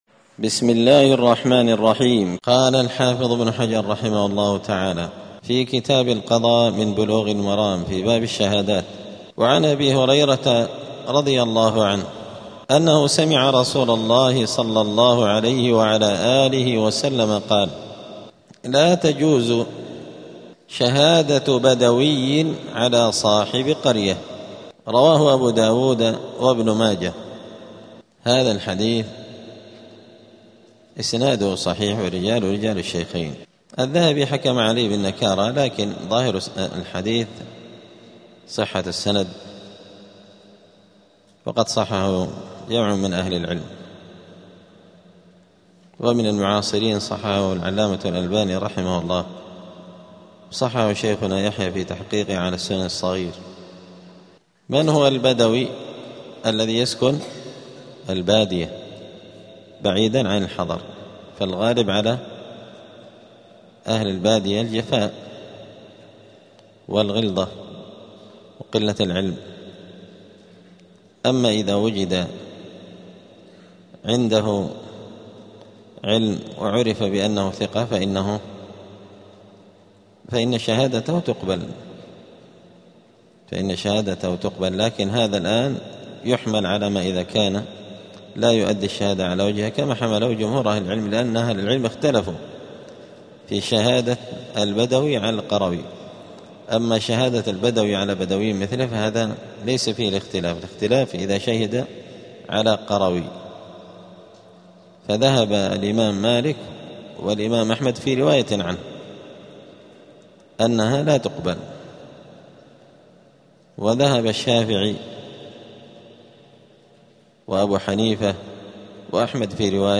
*الدرس السابع عشر (17) {شهادة البدوي}*